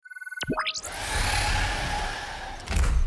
door_sound.mp3